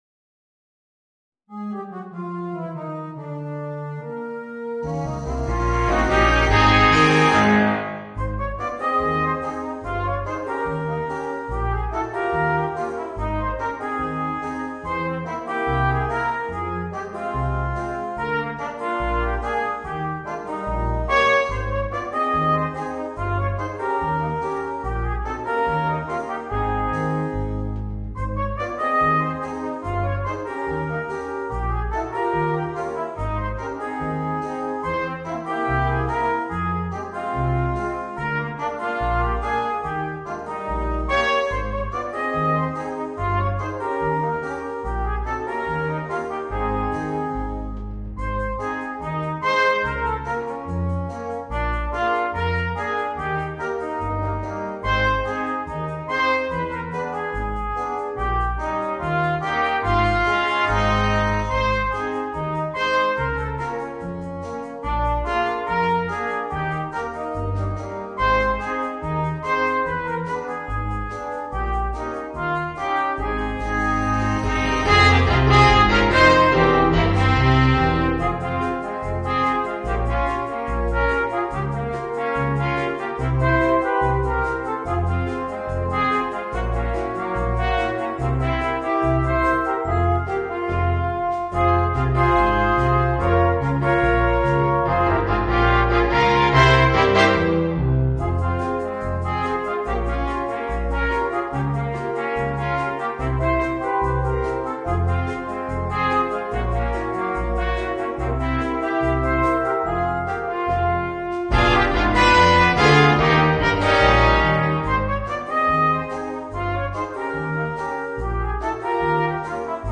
Voicing: 2 Trumpets, Horn, Trombone and Drums